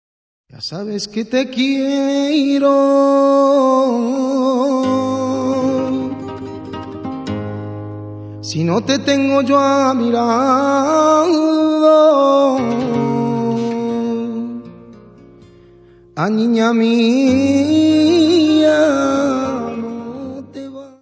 guitarra, voz e palmas
: stereo; 12 cm
Music Category/Genre:  World and Traditional Music